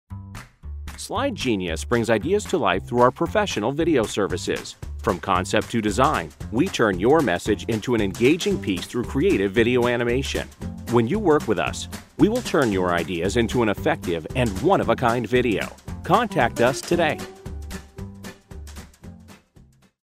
Voice over examples
Male Voice 1